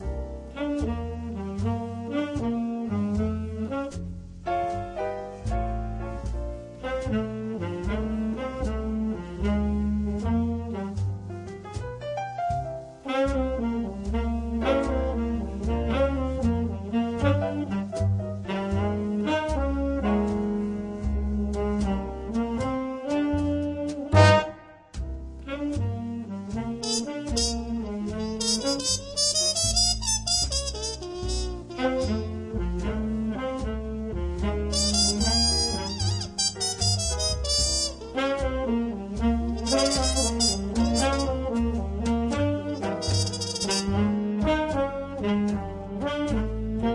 Recorded Eastcote Studios, West London 2006